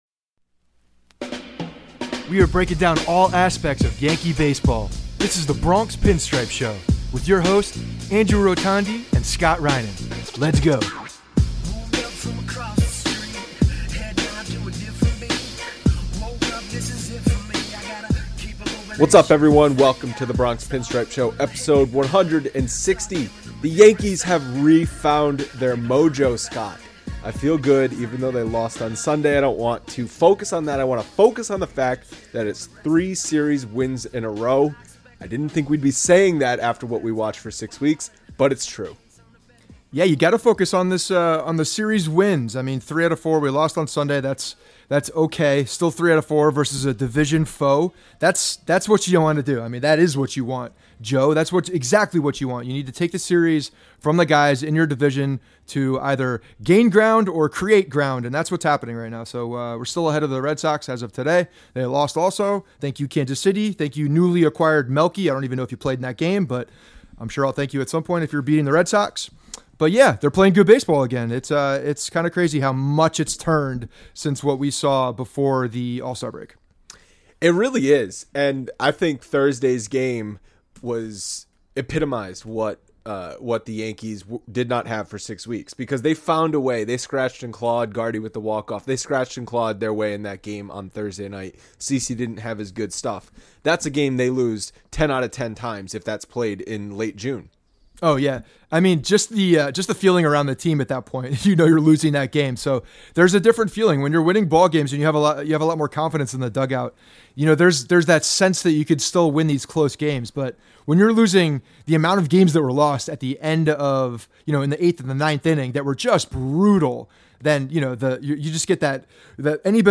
We end the show with listener voicemails.